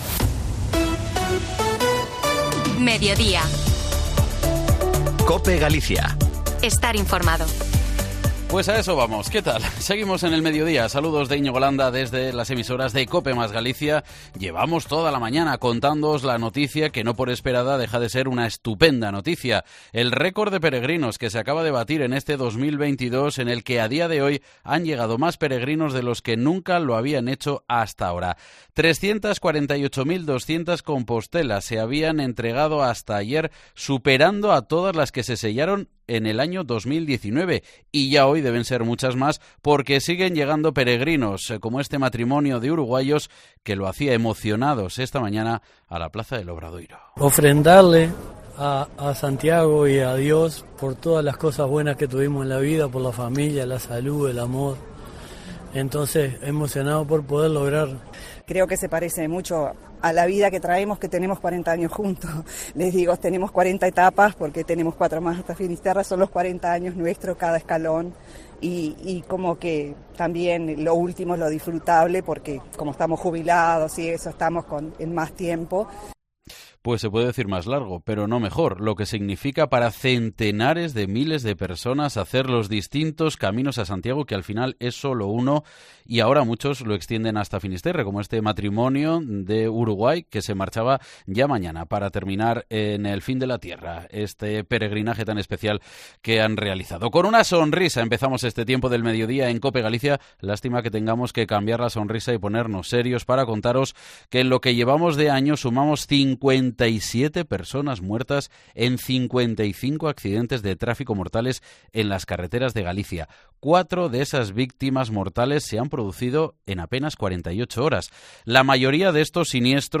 Además, escuchamos los testimonios de los peregrinos que llegan a Santiago tras batirse, a falta de más de tres meses para que termine 2022, la marca récord de cualquier año anterior. Y repasamos lo más destacados en las redes sociales de Galicia.